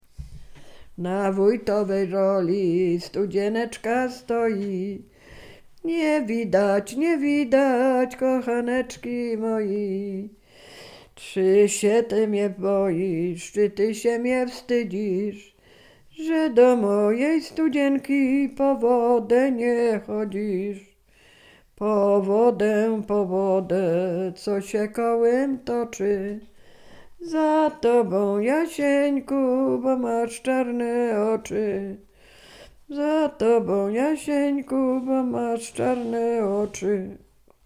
Sieradzkie
liryczne miłosne